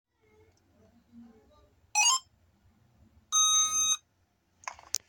HS470 DRONE'S POWER UP SOUND